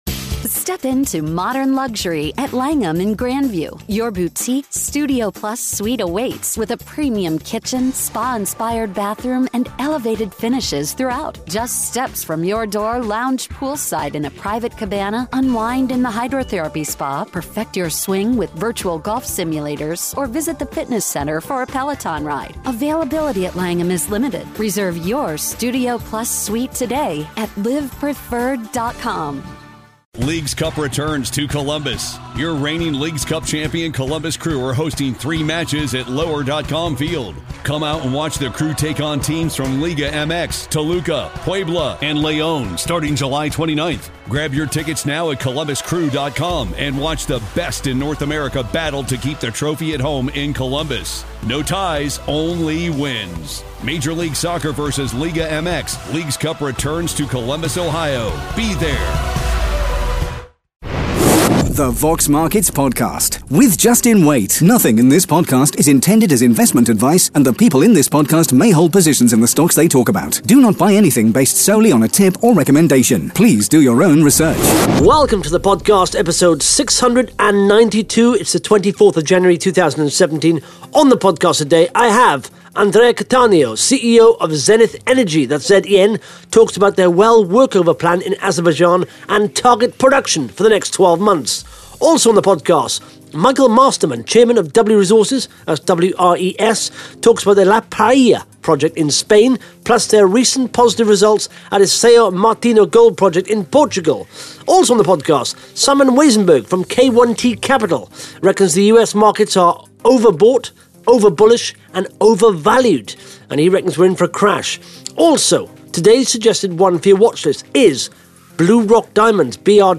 (Interview starts at 1 minute 24 seconds)
(Interview starts at 8 minutes 59 seconds)